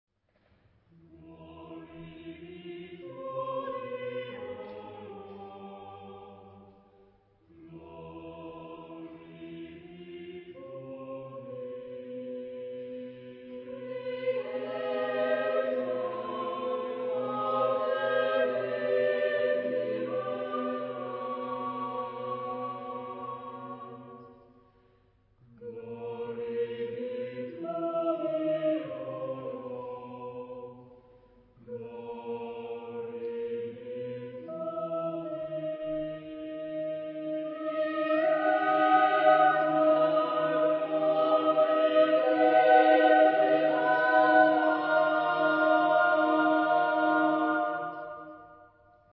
Genre-Stil-Form: geistlich
Chorgattung: SATB + SATB  (8 Doppelchor Stimmen )